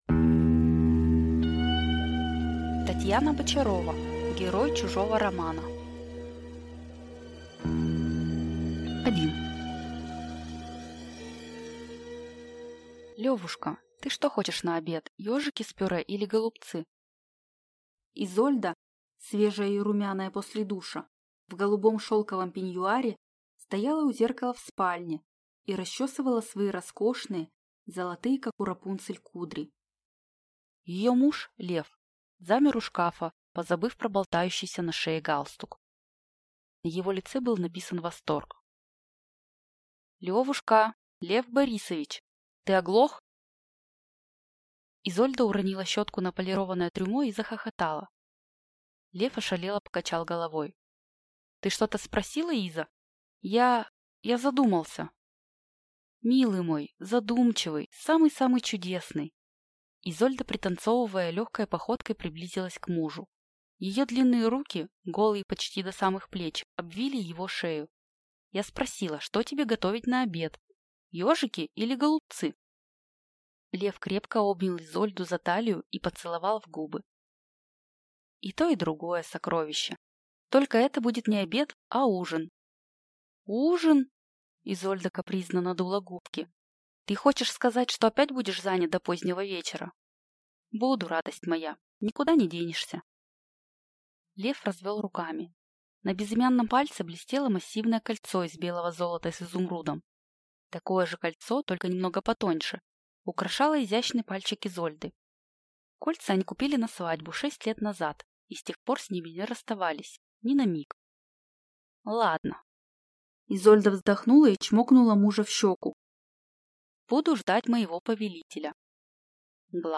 Аудиокнига Герой чужого романа | Библиотека аудиокниг